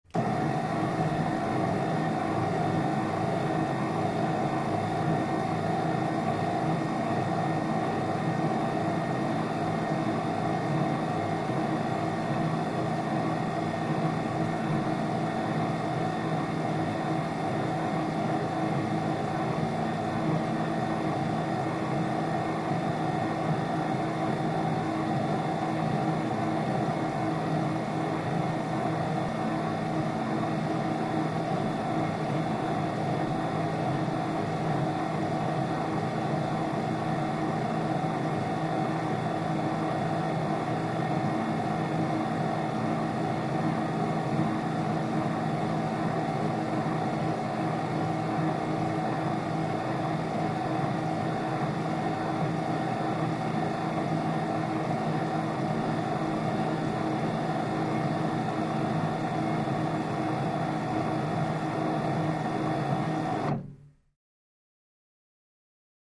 Light Bar Clicks On Police Car; On, Modulating High Pulsing Whine Steady, Off, Close Perspective